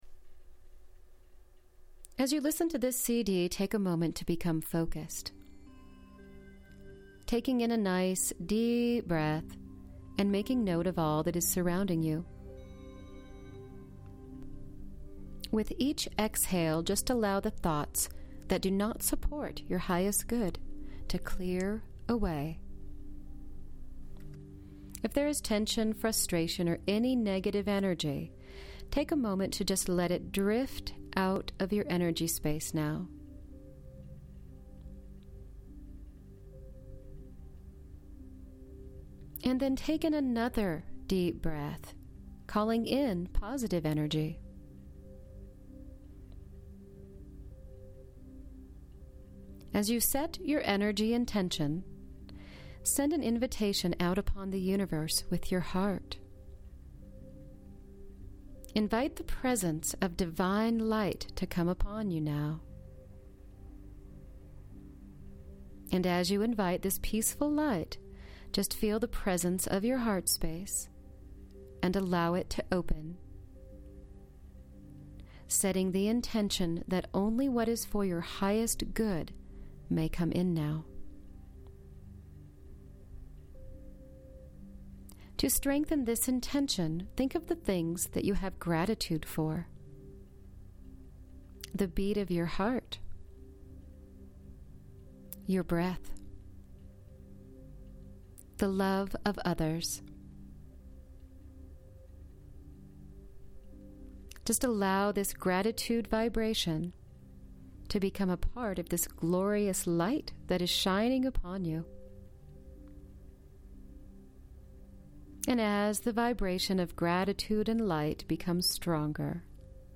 Free Guided Meditation